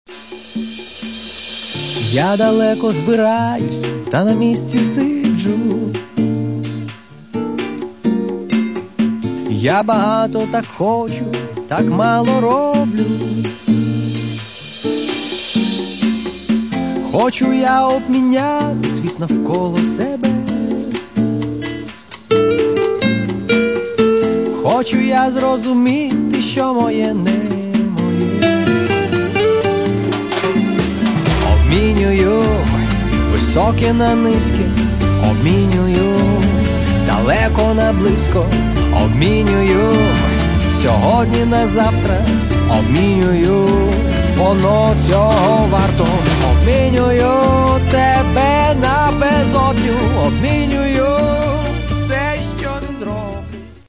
Каталог -> Рок та альтернатива -> Поп рок